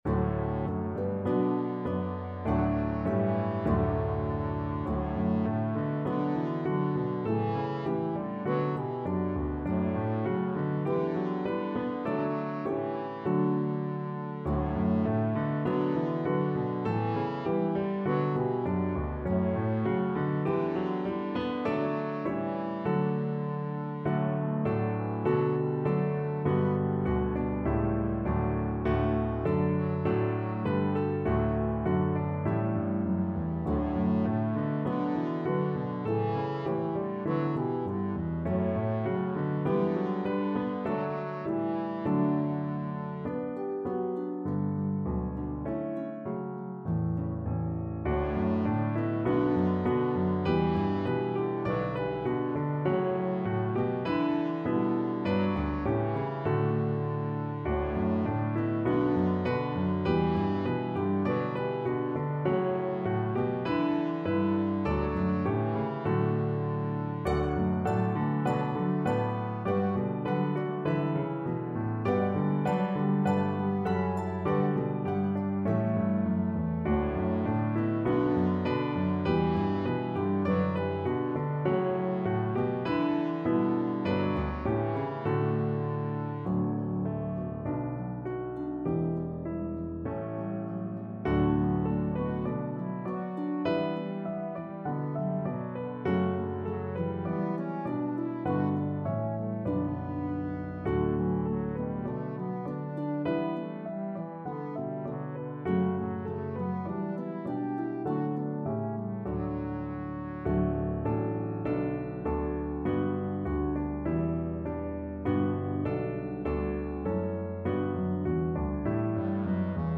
a pentatonic melody